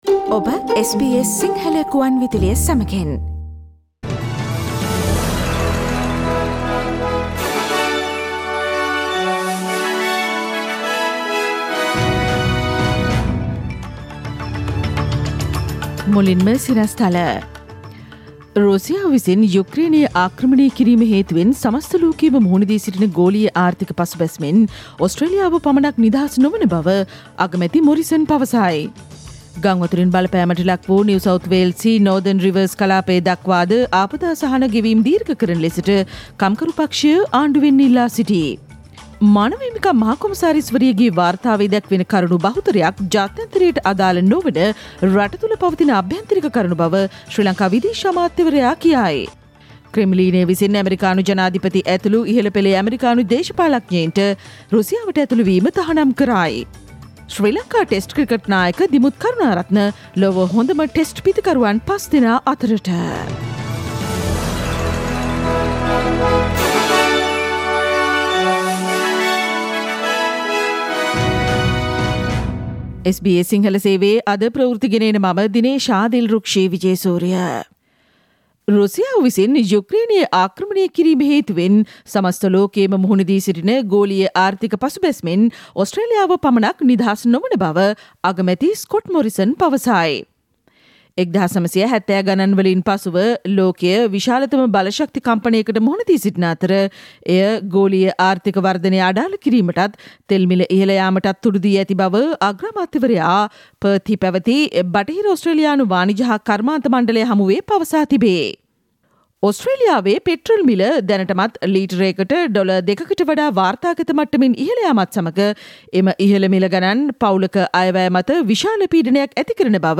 ඉහත චායාරූපය මත ඇති speaker සලකුණ මත click කොට මාර්තු 17 වන බ්‍රහස්පතින්දා SBS සිංහල ගුවන්විදුලි වැඩසටහනේ ප්‍රවෘත්ති ප්‍රකාශයට ඔබට සවන්දිය හැකියි.